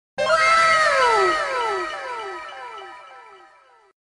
WOW (anime Sound)